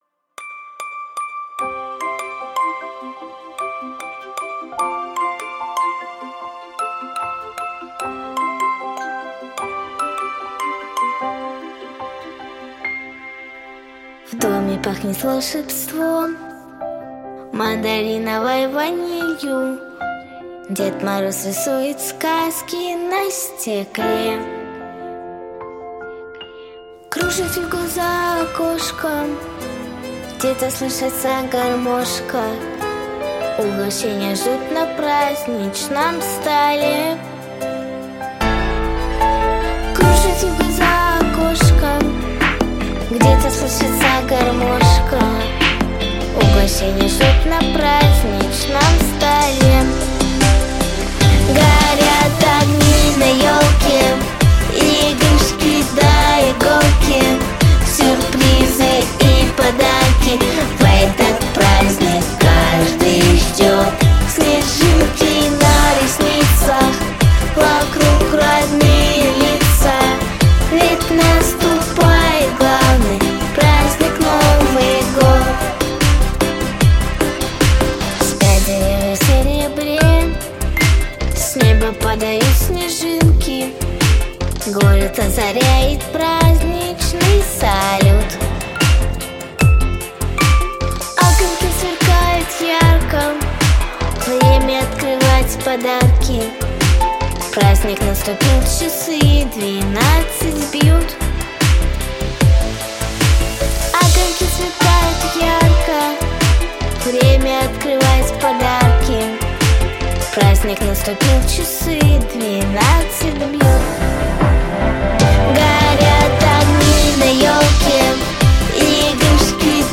Главная » Песни » Новогодние песни